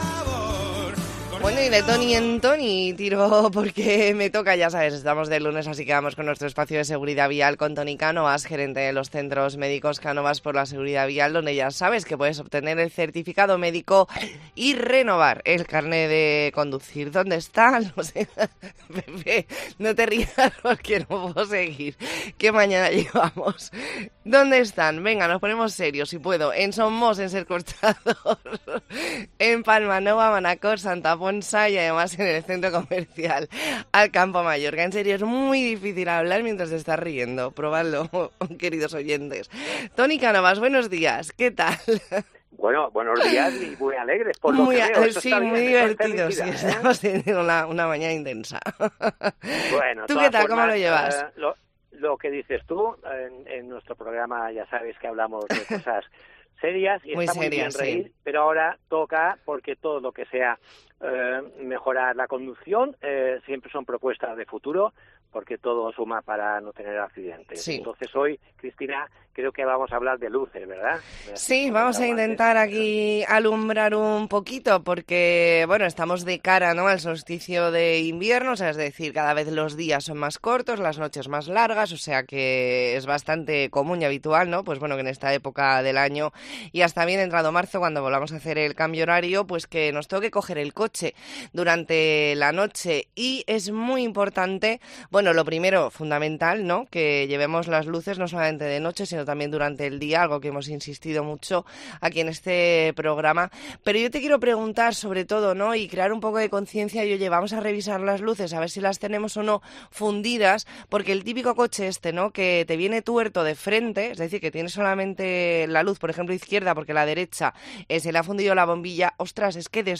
Entrevista en La Mañana en COPE Más Mallorca, lunes 11 de diciembre de 2023.